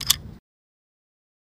Gas Cap | Sneak On The Lot